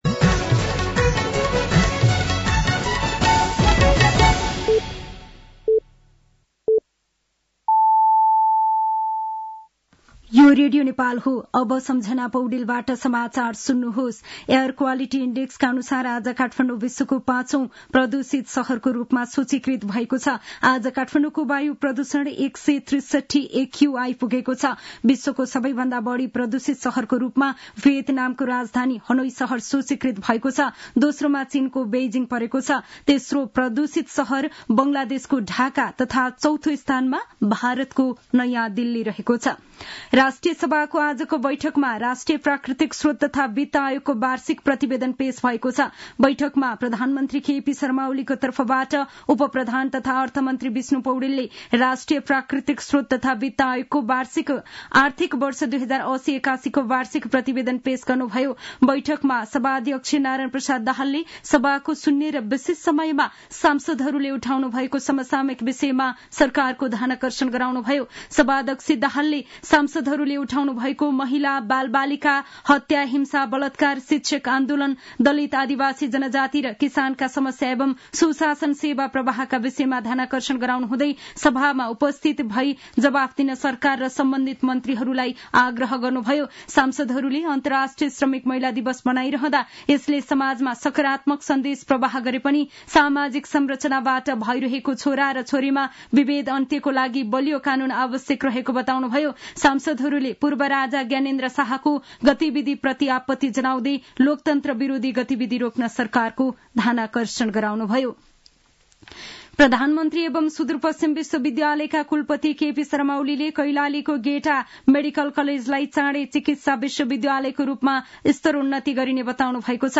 साँझ ५ बजेको नेपाली समाचार : २६ फागुन , २०८१